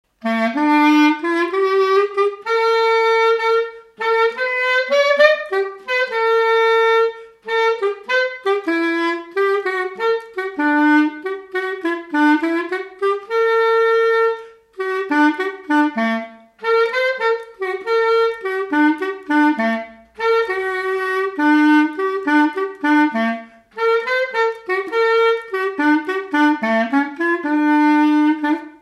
Résumé instrumental
circonstance : fiançaille, noce
Pièce musicale inédite